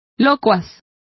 Complete with pronunciation of the translation of chattiest.